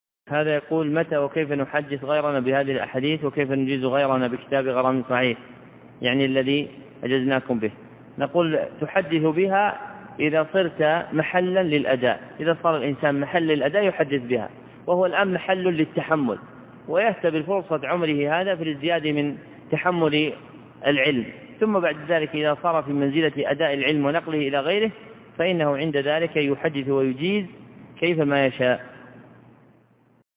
205. 08 - متى نحدث غيرنا بهذه الأحاديث؟ الدرس الواحد الخامس